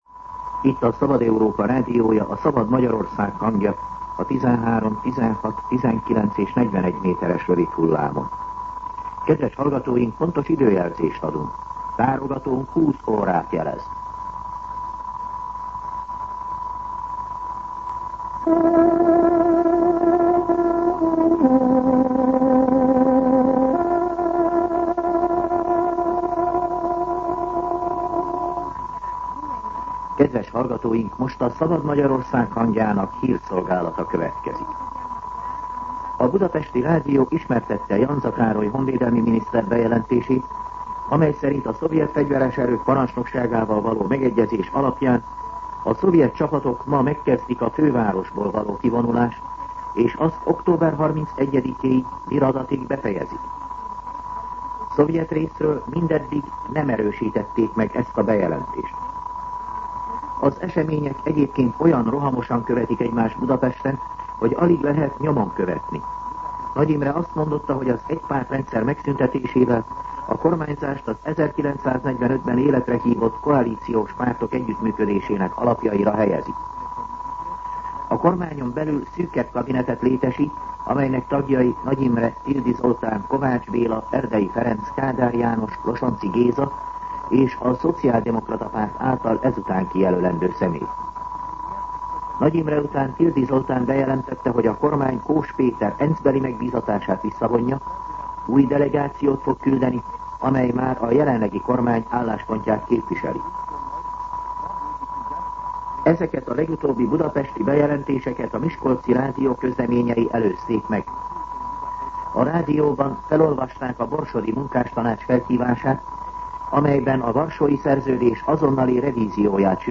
20:00 óra. Hírszolgálat